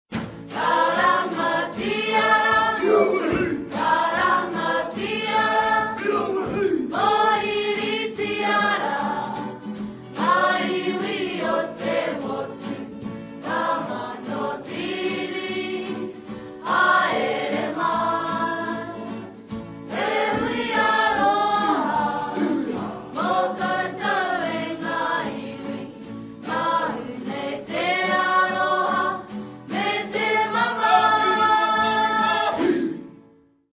En faite c'est de la musique traditionelle maori, au vu de certain film et docu ne simple Folk suffit mais j'aimerais confirmation de votre par.
je met un extrait pour que vous entendiez la guitare, desolé, on ne l'entend pas très bien car les voix sont msie en avant.
Après l'écoute j'hésite entre une Folk ou une guitare de type selmer (Guitare Jazz Manouche).